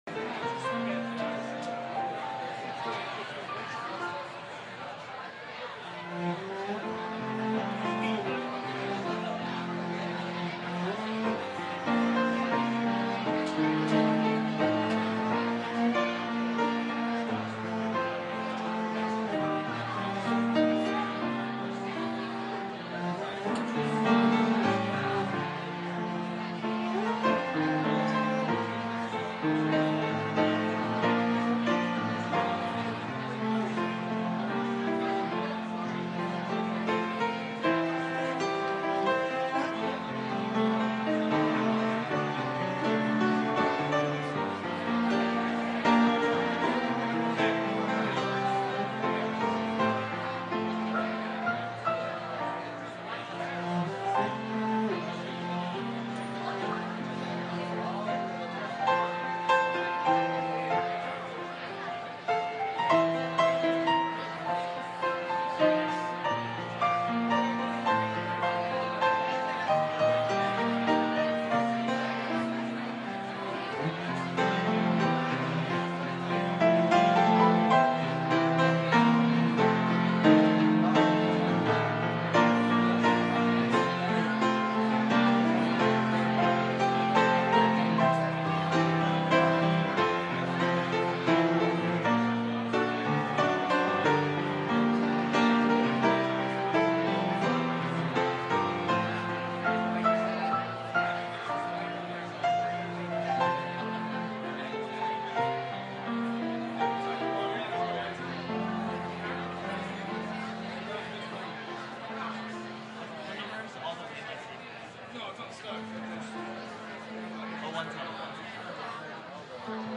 Mens_Preaching_Night.mp3